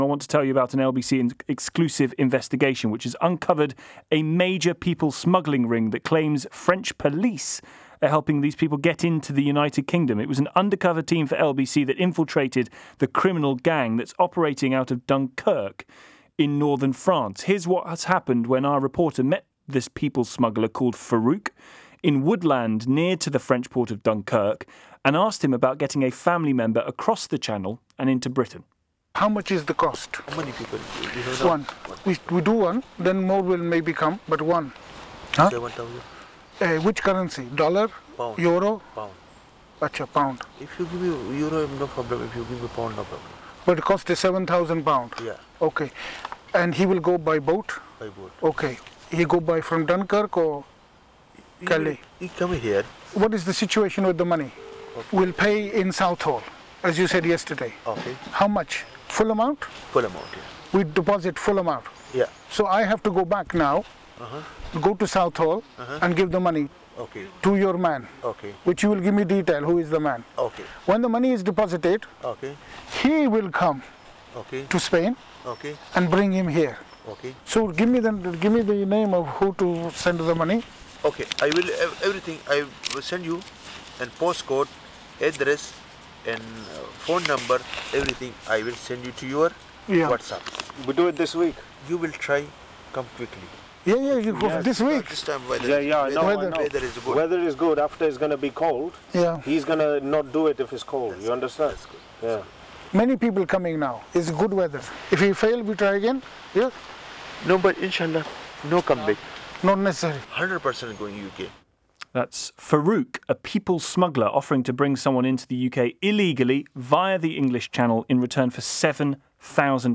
Listen to the full radio interview here: